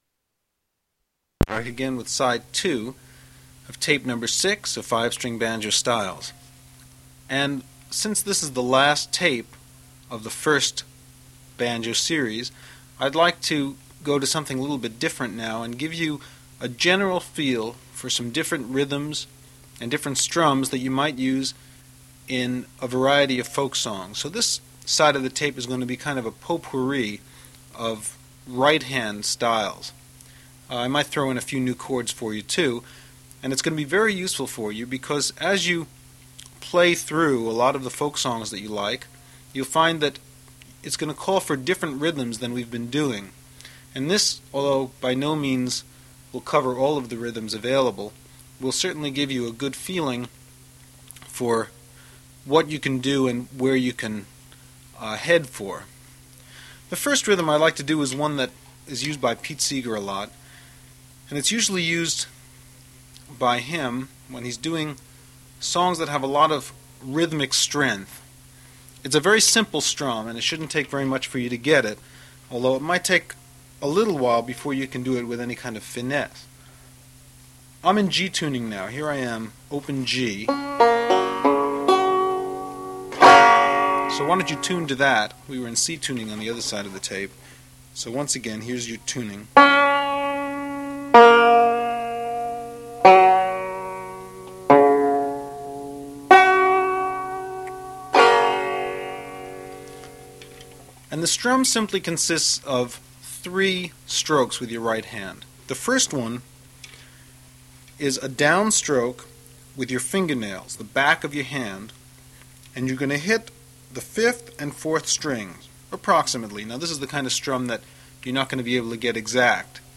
Happy Traum Banjo Lessons
banjo-6-2.mp3